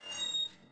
1 channel
squeak.mp3